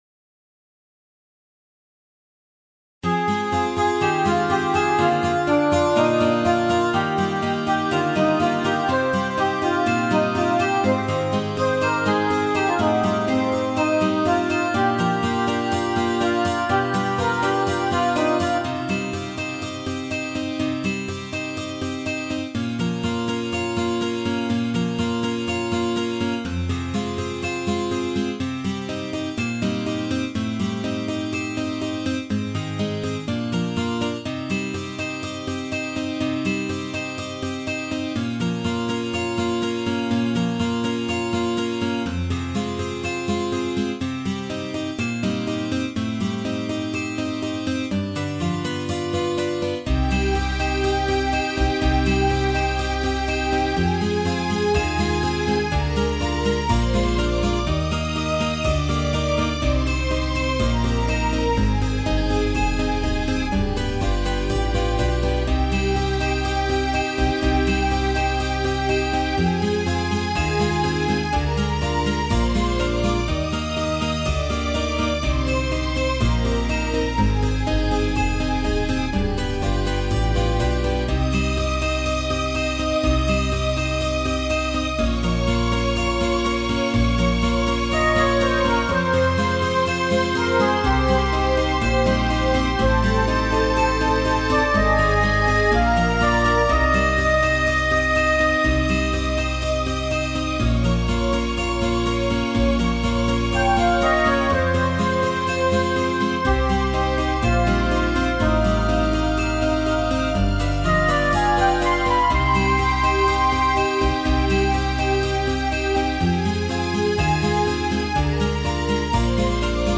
минусовка версия 98309